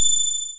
ping.wav